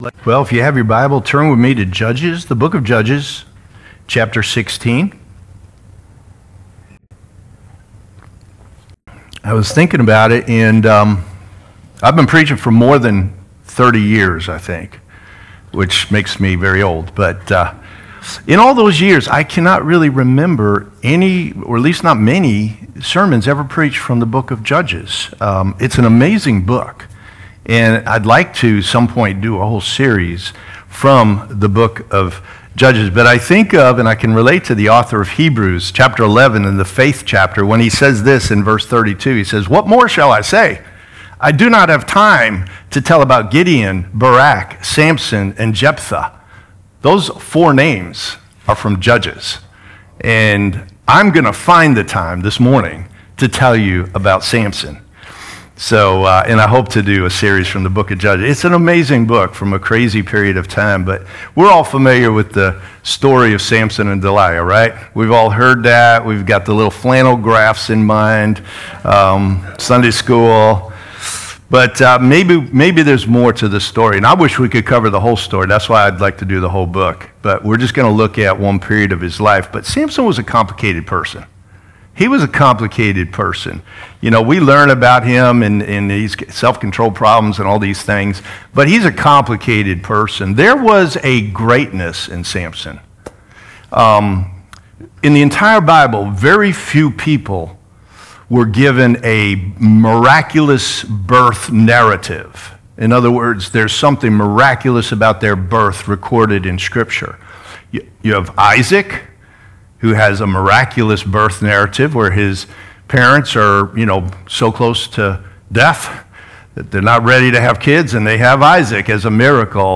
Grace Community Church - Corning - Painted Post … continue reading 150 episodios # Religion # Grace Community # Sermon Series # Christianity # Grace Community Church Painted Post